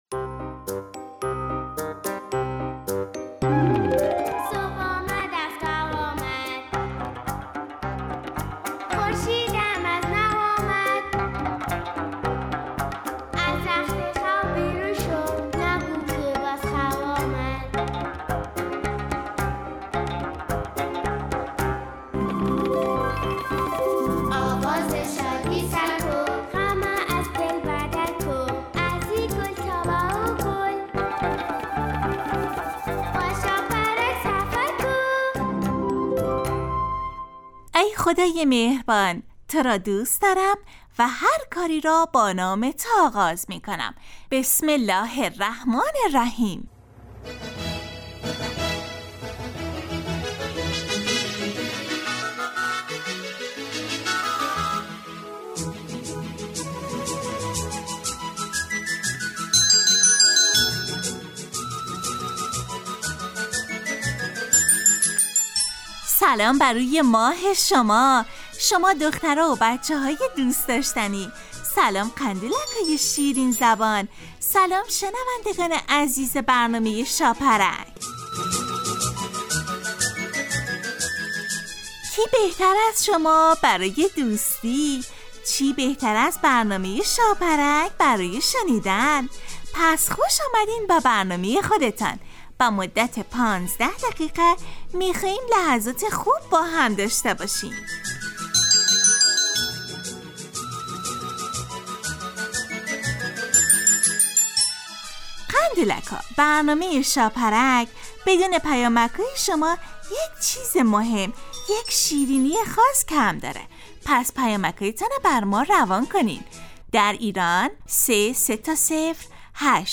شاپرک برنامه ای ترکیبی نمایشی است که برای کودکان تهیه و آماده میشود.این برنامه هرروز به مدت 15 دقیقه با یک موضوع مناسب کودکان در ساعت 8:45 صبح به وقت افغانستان از رادیو دری پخش می گردد.